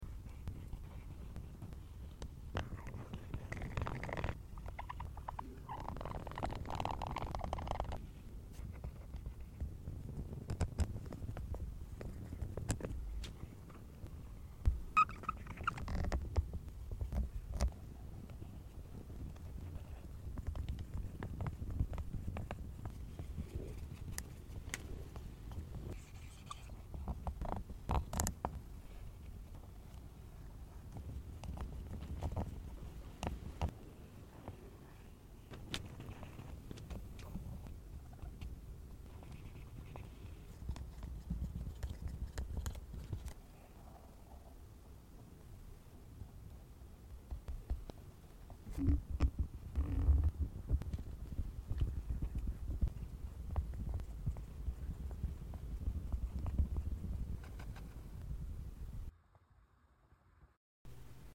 ASMR August calendar! 🤠🌾🌵🍒 Here sound effects free download